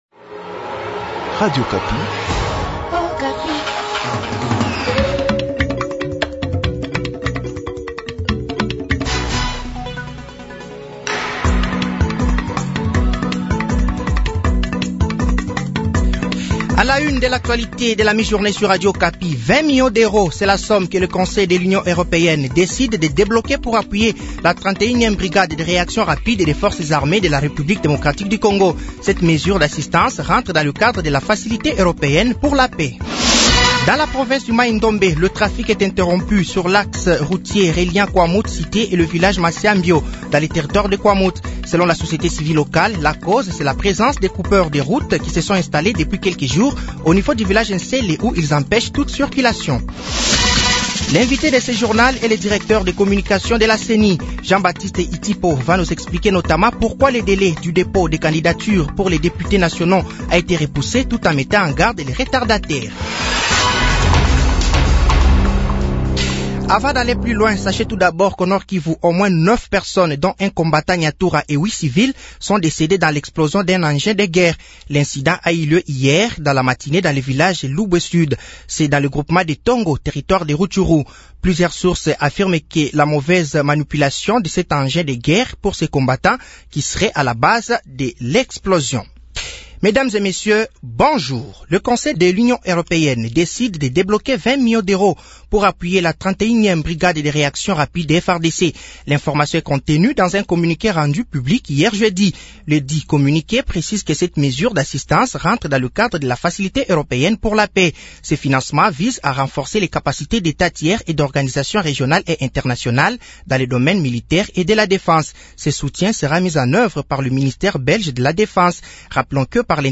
Journal français de midi de ce vendredi 21 juillet 2023